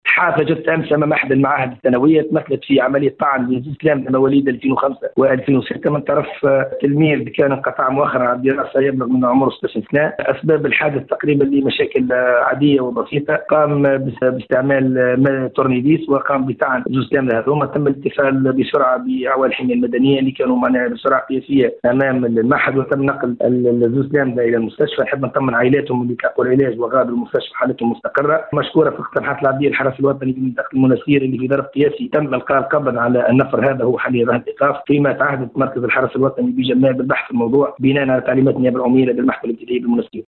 المنستير: بواسطة "مفك براغي" ... تلميذ منقطع عن الدراسة يعتدي على تلميذين (تسجيل) - Radio MFM